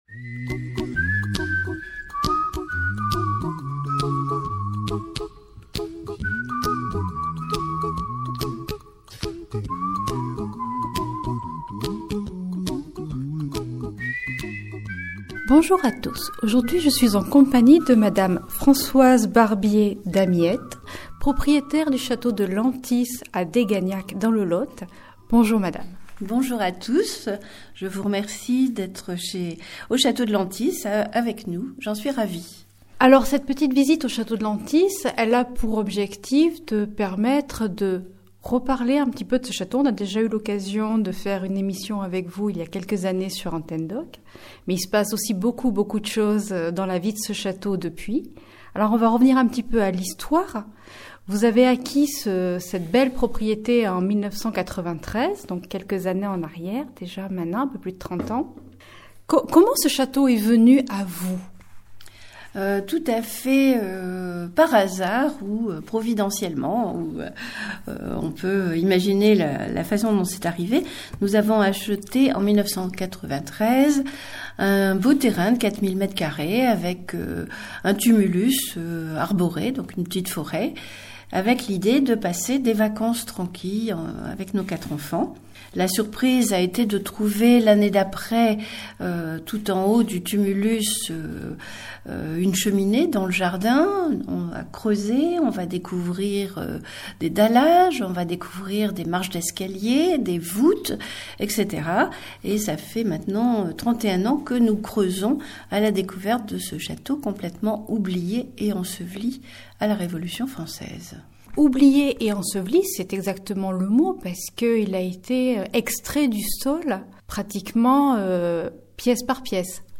Fenêtre sur soi - visite au château de Lantis à Dégagnac - Antenne d'Oc